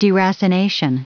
Prononciation du mot deracination en anglais (fichier audio)